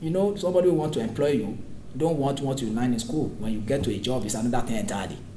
S1 = Bruneian female S3 = Nigerian male
These word are pronounced as [ʌndʌtɪ] , with the voiced TH in another pronounced as [d] , and no vowel between the [n] and [d] to correspond to the second syllable of another .